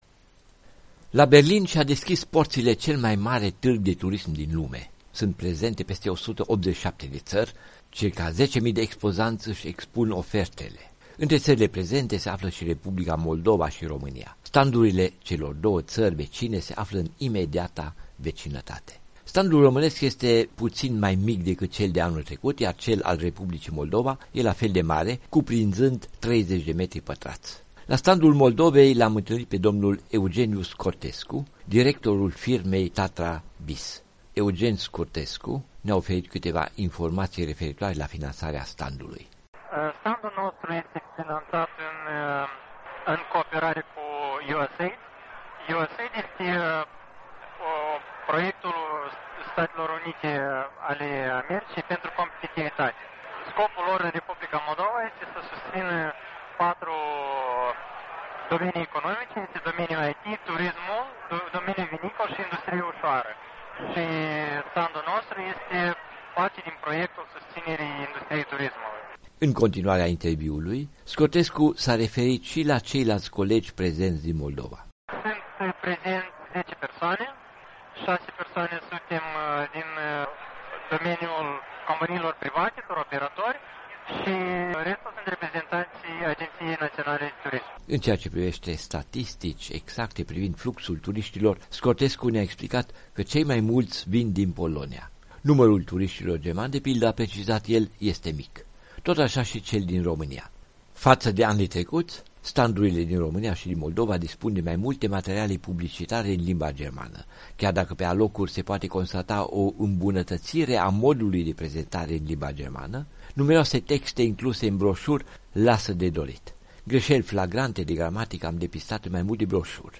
Impresii de la Tîrgul Internaţional de Turism de la Berlin (ITB).